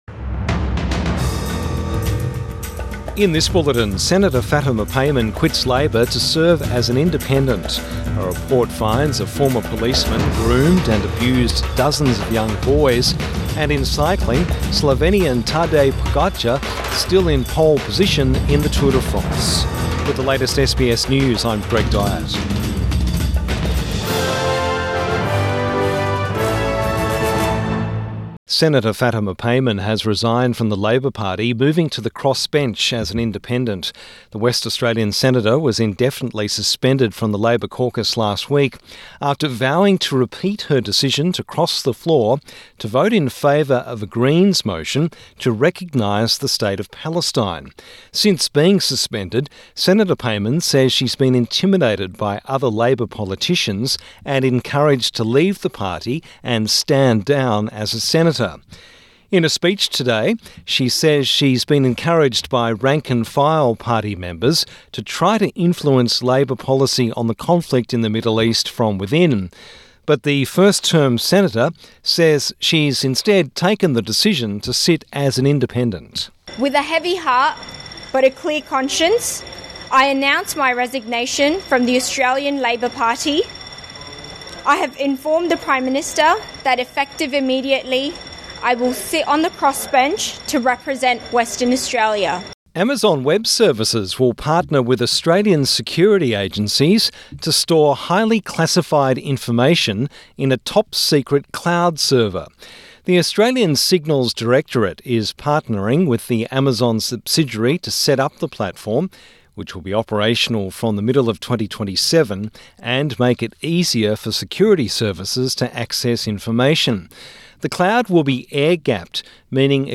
Evening News Bulletin 4 July 2024